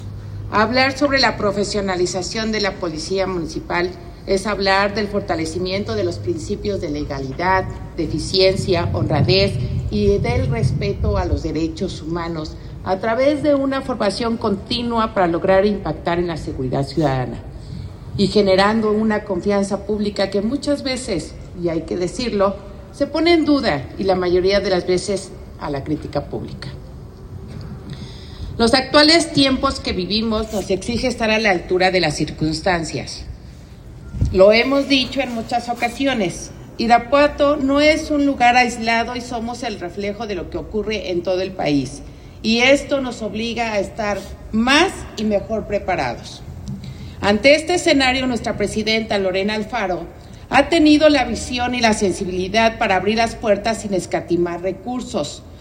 AudioBoletines
Consuelo Cruz Galindo, secretaria de Seguridad Ciudadana